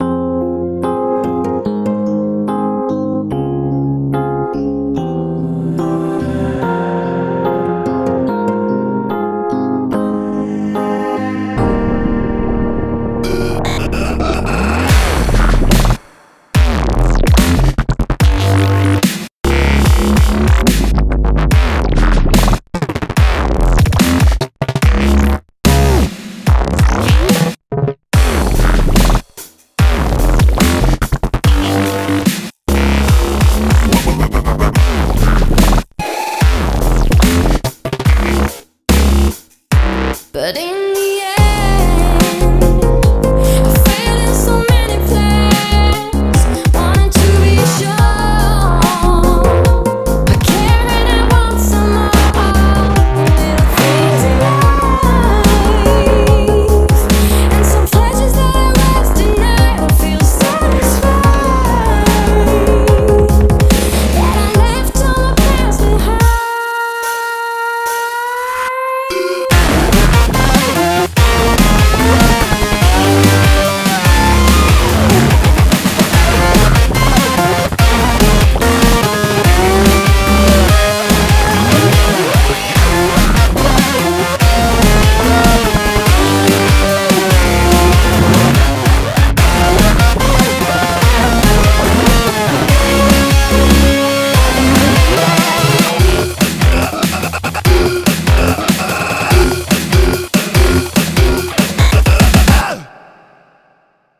BPM36-218
MP3 QualityMusic Cut